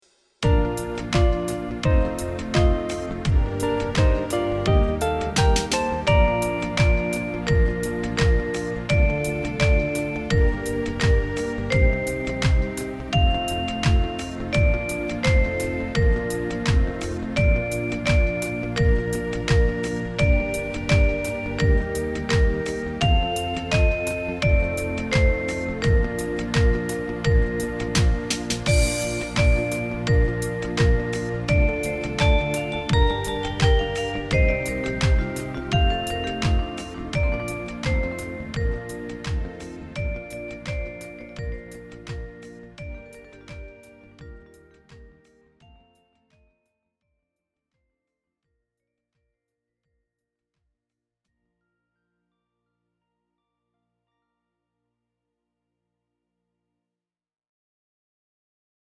Maza dziesmiņa Play-along.